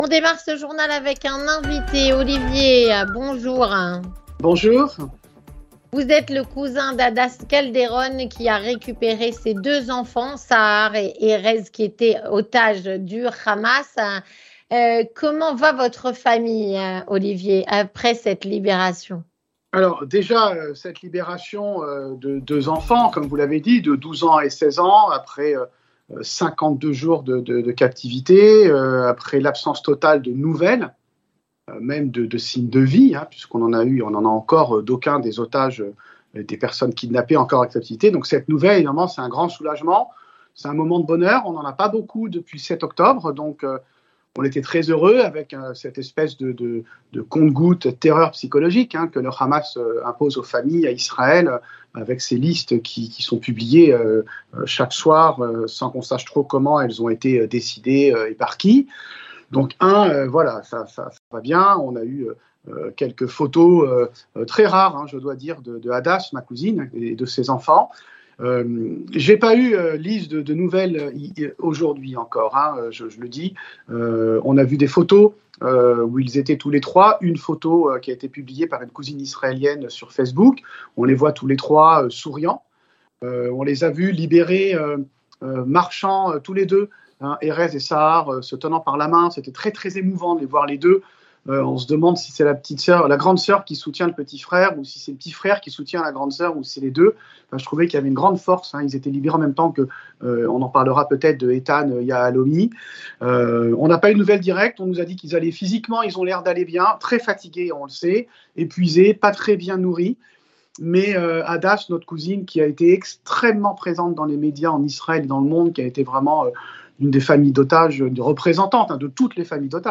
Témoignage d'un membre de la famille.